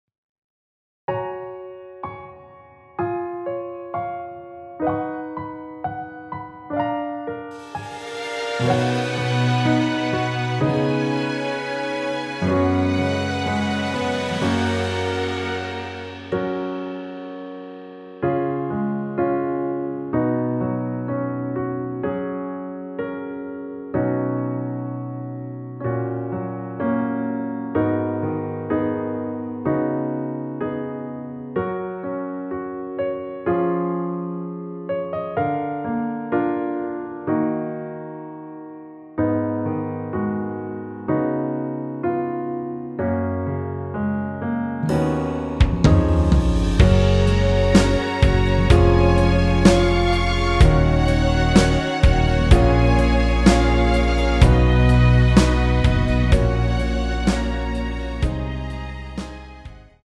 원키에서(+5)올린(1절앞+후렴)으로 진행되는 MR입니다.
F#
앞부분30초, 뒷부분30초씩 편집해서 올려 드리고 있습니다.
중간에 음이 끈어지고 다시 나오는 이유는